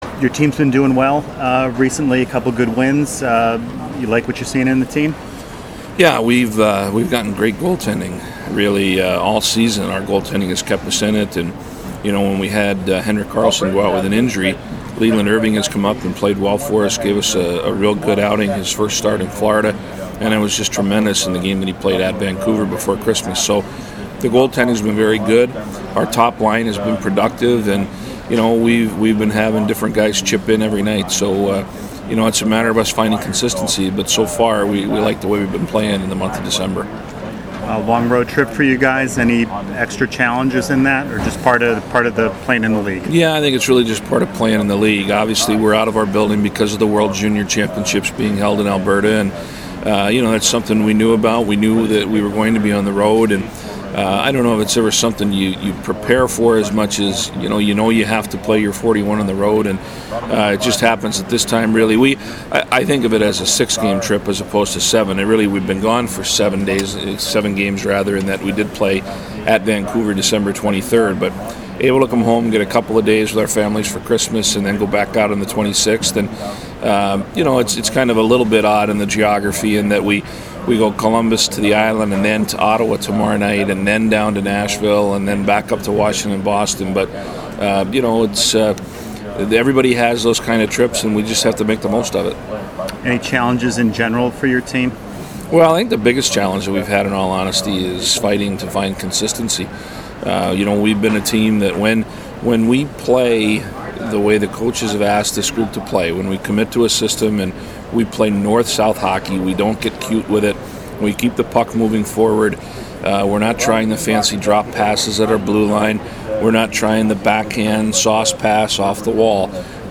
Feaster Interview